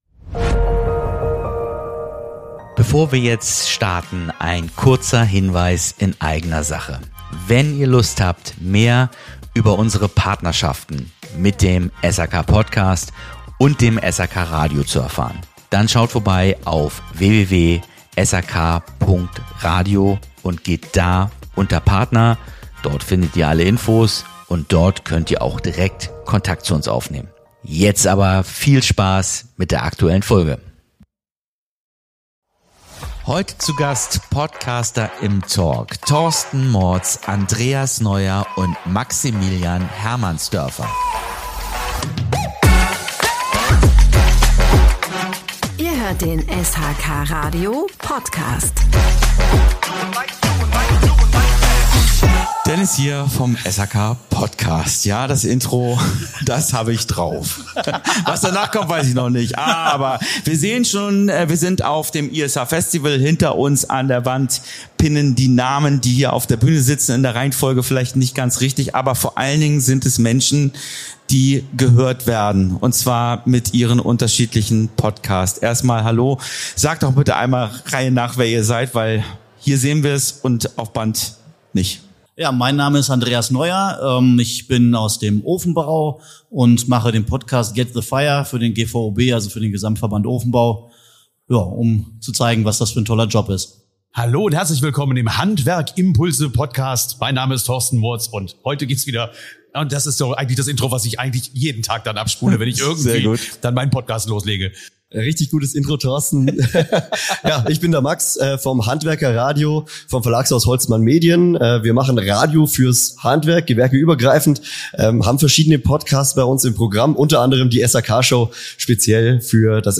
Ein lockerer, ehrlicher Talk über das Podcasten in der SHK- und Handwerksbranche – mit vielen persönlichen Einblicken und einem seltenen Blick hinter die Kulissen.